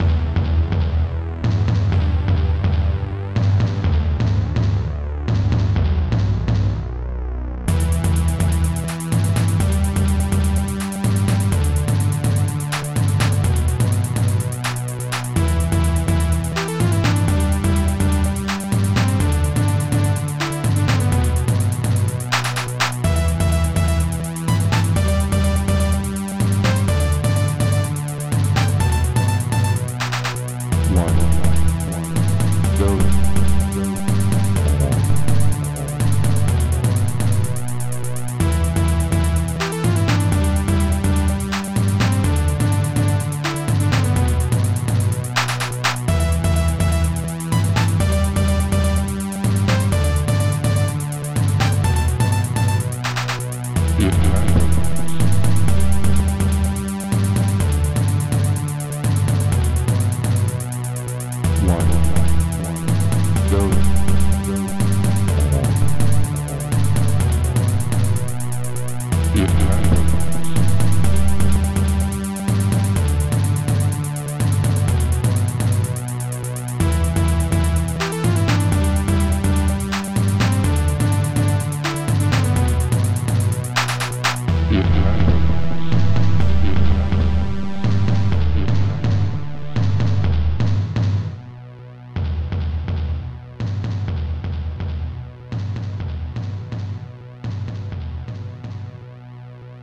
Protracker Module
War Drum Horror Hihat short synth long synth